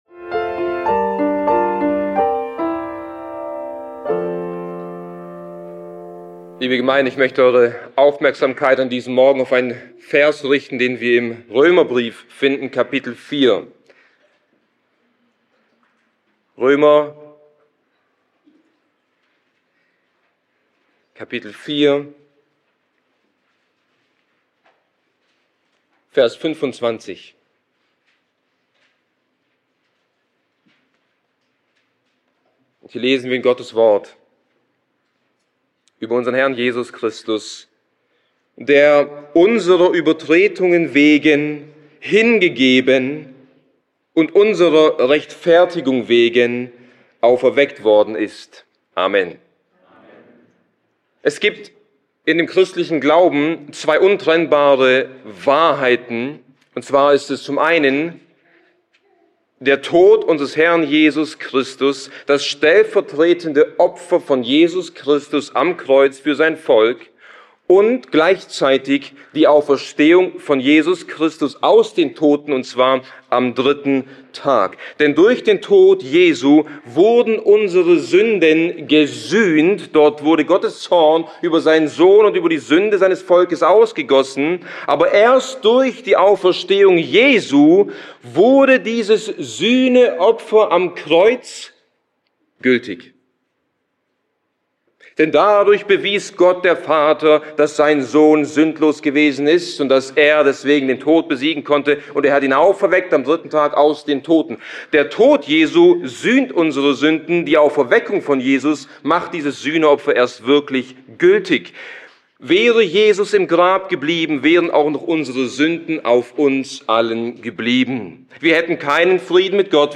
## Details ### Die Notwendigkeit der Auferstehung Die Predigt basiert auf Römer 4,25, welcher zwei untrennbare Wahrheiten des Evangeliums enthält: Jesu Tod für unsere Übertretungen und seine Auferstehung für unsere Rechtfertigung. Der Tod Jesu sühnte unsere Sünden, aber erst durch die Auferstehung wurde dieses Sühneopfer gültig.